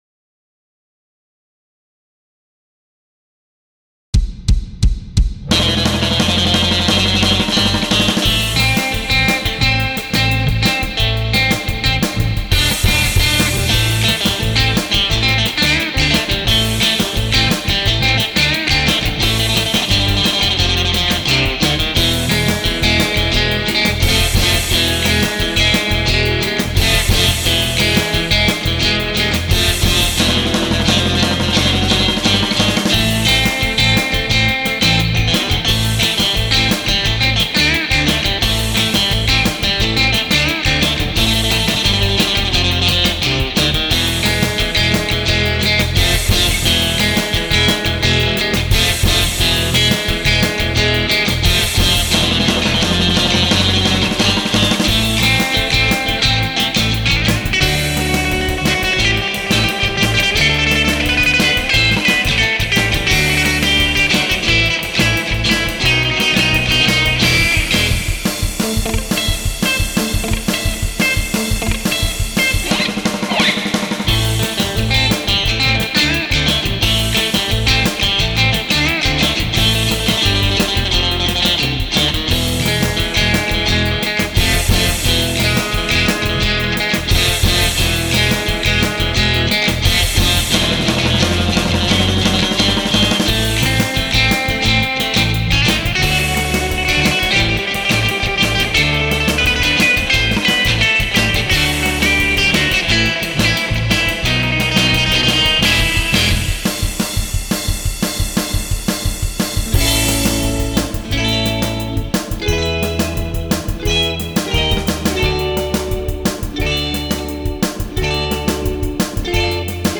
Jazz-O-Caster #2 - Doing all the guitar work